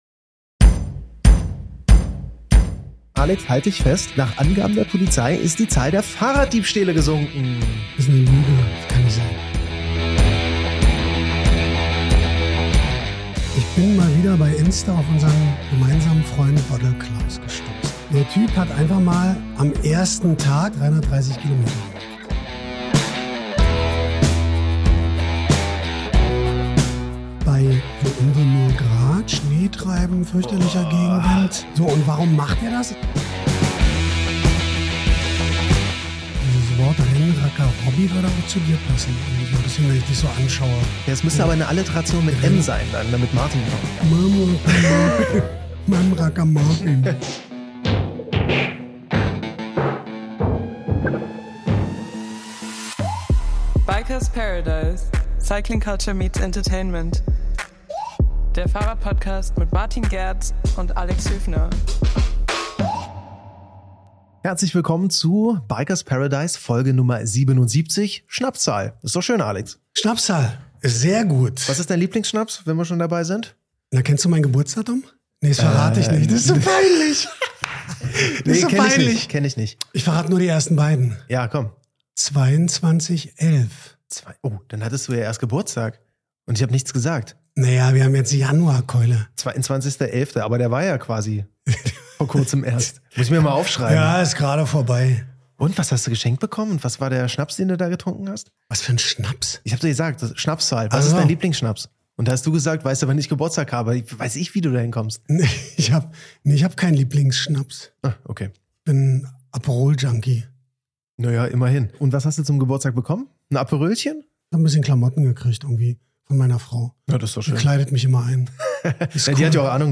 Neujahrsphilosophie im Studio - warum fahren Menschen eigentlich Fahrrad?